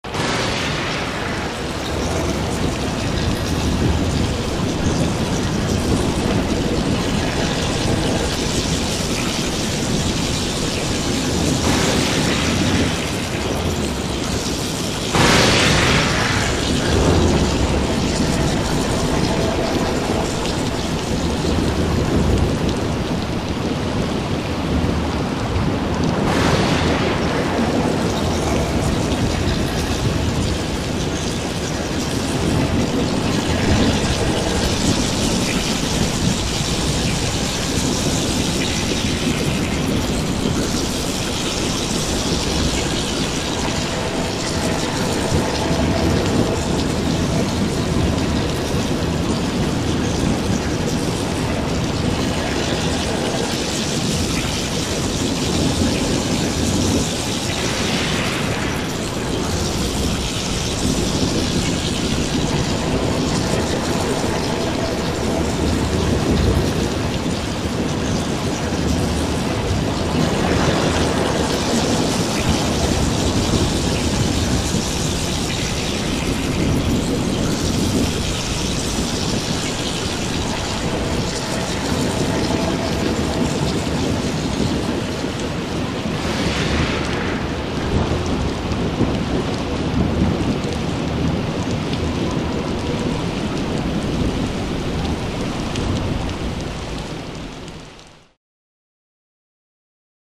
Alien Storm; Synthetic Thunderstorm And Acid Rain Splats.